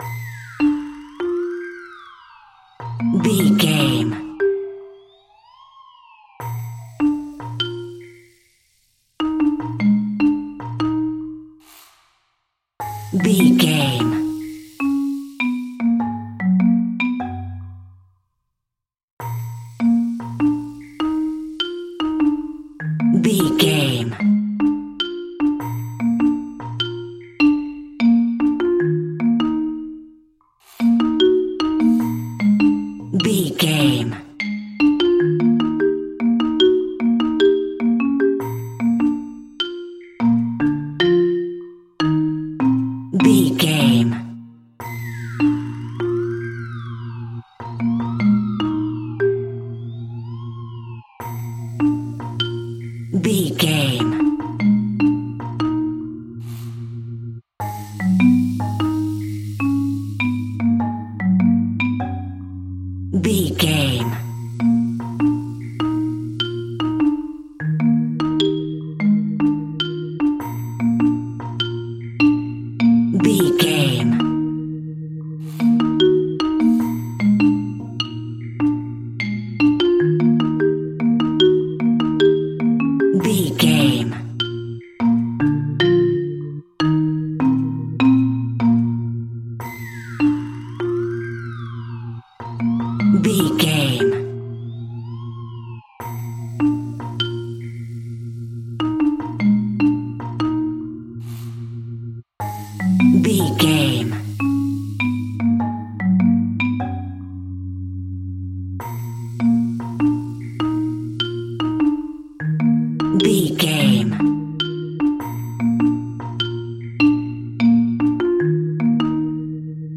Ionian/Major
B♭
Slow
silly
playful
bumbling
kids music
vibes
percussion
xylophone